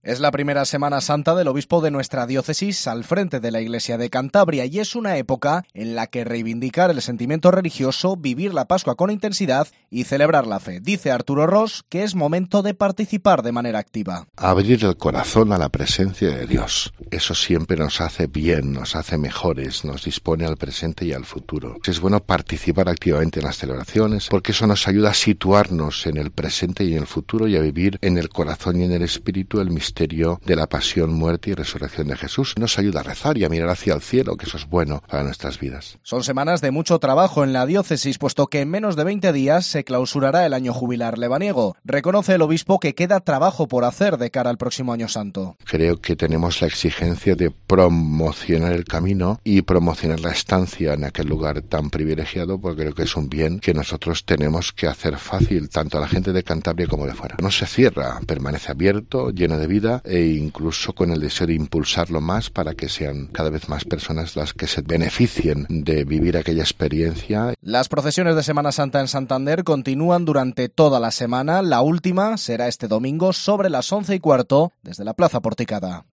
Ros ha dedicado unos momentos a los oyentes de la Cadena COPE para compartir sus reflexiones en medio de un periodo crucial tanto para la Iglesia como para Cantabria.